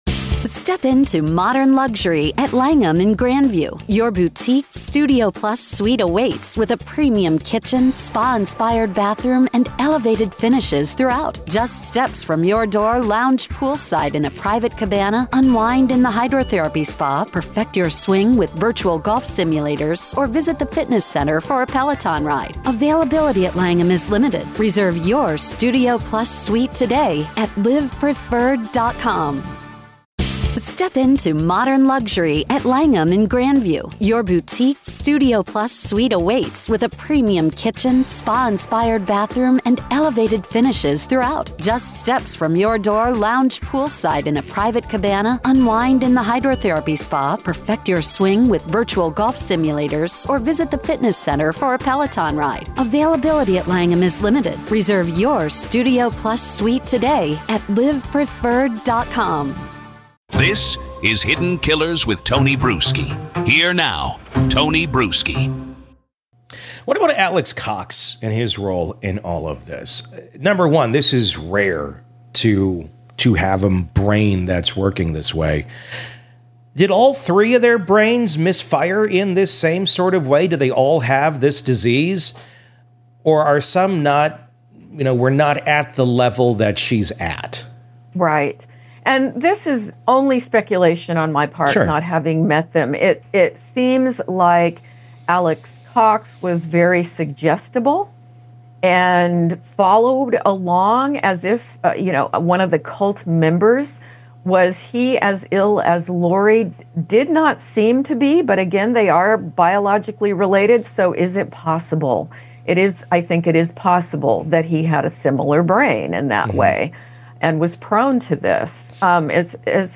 The conversation dives into the eerie world of shared psychosis, the cult-like mindset that kept them insulated from reality, and the tragic fallout for the victims’ families.